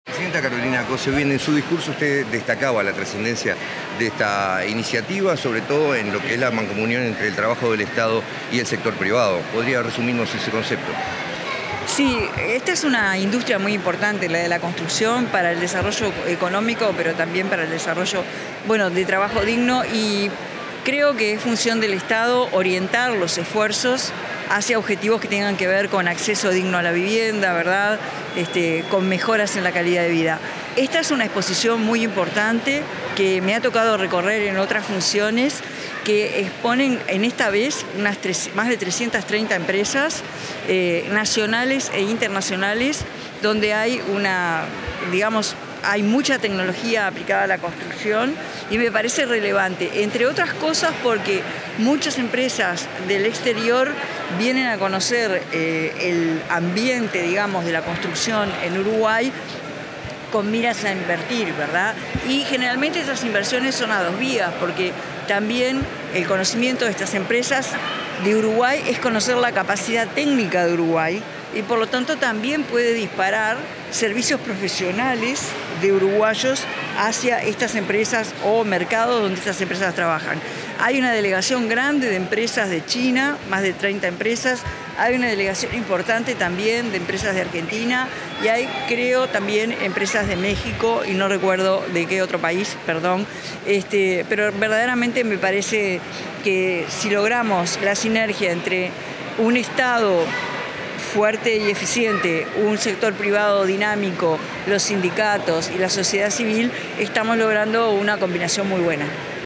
Declaraciones de la presidenta de la República en ejercicio, Carolina Cosse
Declaraciones de la presidenta de la República en ejercicio, Carolina Cosse 16/10/2025 Compartir Facebook X Copiar enlace WhatsApp LinkedIn Tras participar en la inauguración de la Feria de la Construcción 2025, la presidenta de la República en ejercicio, Carolina Cosse, brindó declaraciones a la prensa.